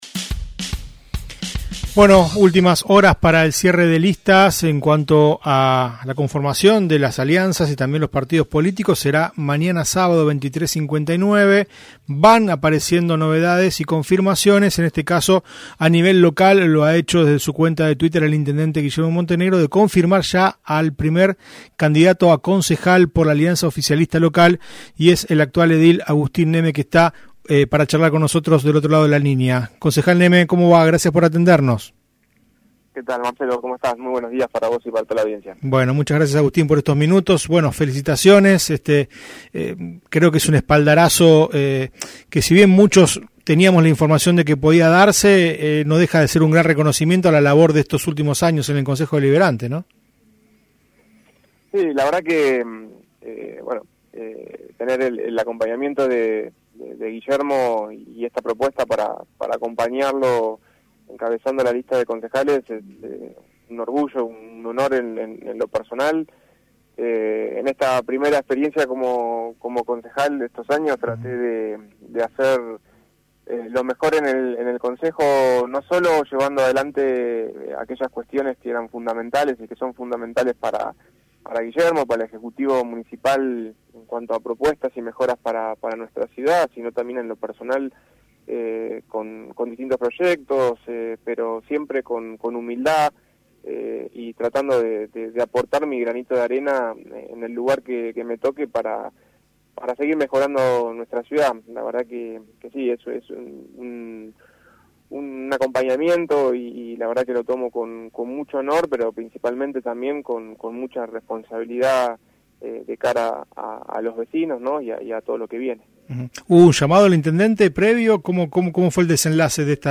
Luego de ser confirmado por el intendente Montenegro como primer concejal de Juntos en Mar del Plata, el actual edil y jefe de bloque oficialista Agustín Neme dialogó con "UPM", luego del voto de confianza por parte del jefe comunal.